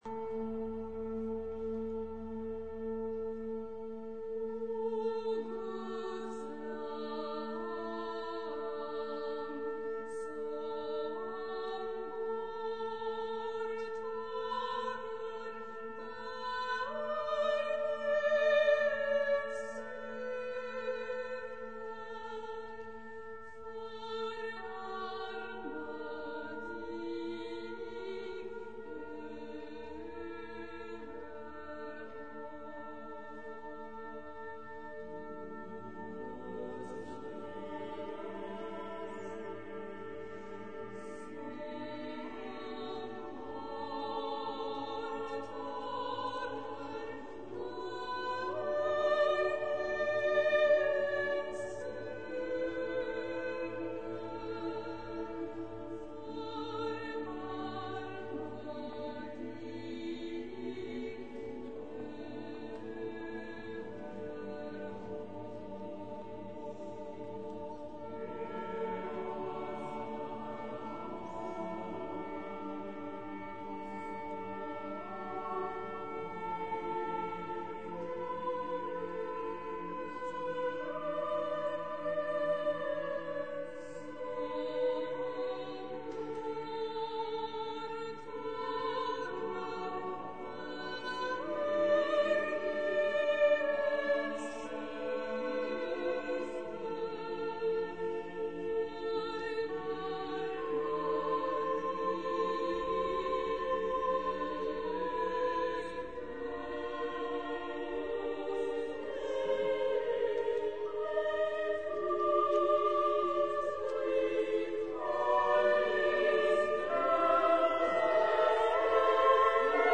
for Soloists, Choir, Wind Orchestra, and Two Organs
80 min Oratorium -